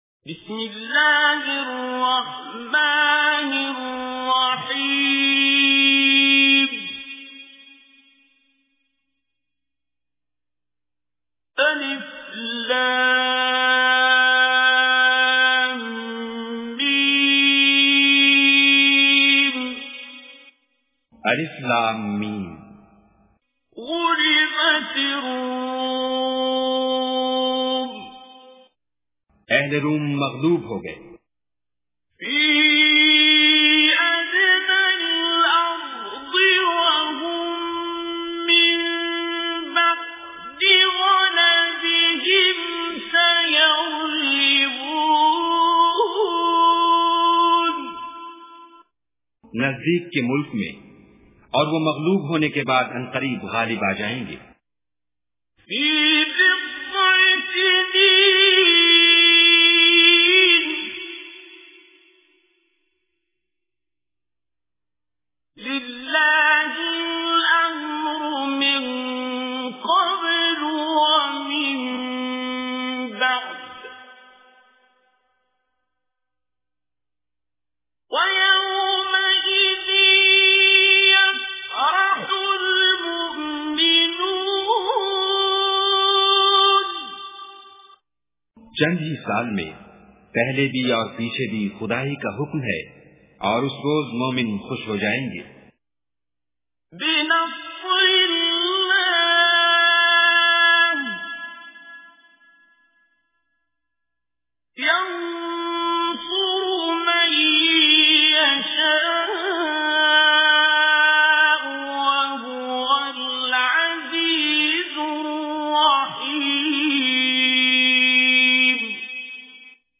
Surah Ar Rum Recitation with Urdu Translation
Listen online and download beautiful Quran Recitation / Tilawat of Surah Ar Rum in the voice of Qari Abdul Basit As Samad.